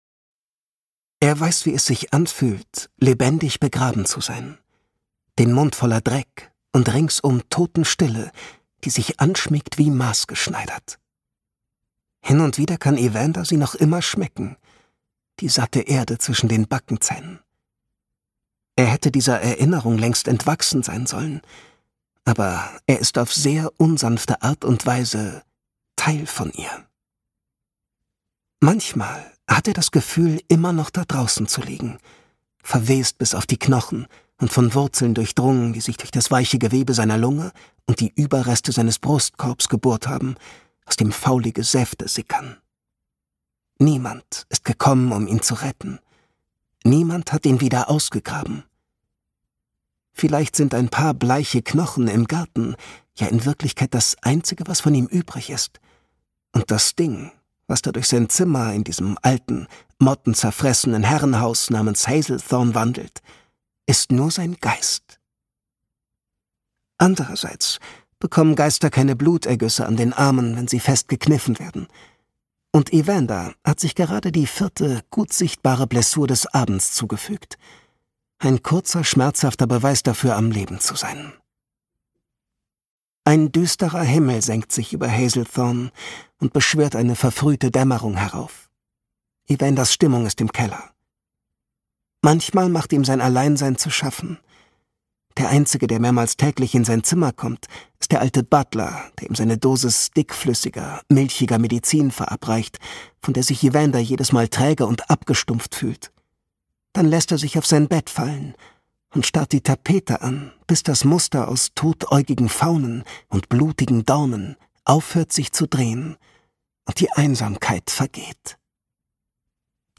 erzählt melancholisch verträumt und düster fesselnd das romantische Schauermärchen. Dabei lotet er die psychologischen Tiefen gekonnt und einfühlsam aus.
Gekürzt Autorisierte, d.h. von Autor:innen und / oder Verlagen freigegebene, bearbeitete Fassung.